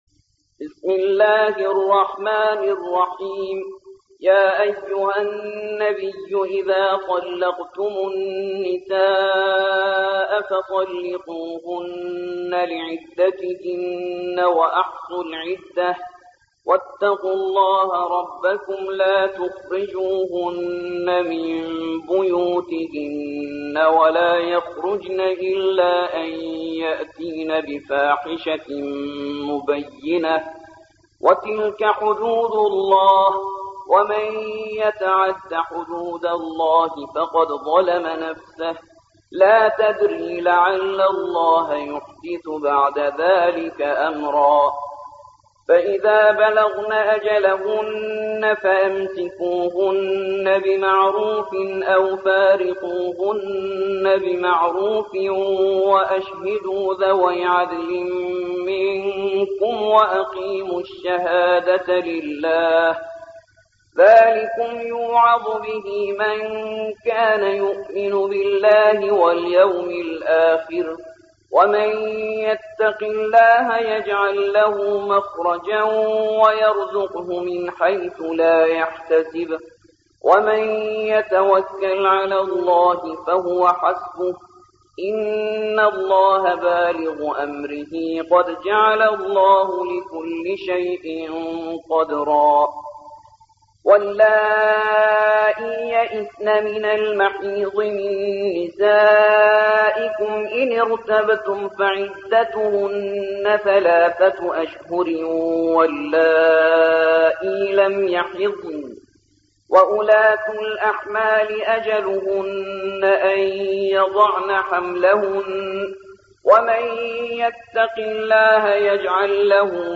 65. سورة الطلاق / القارئ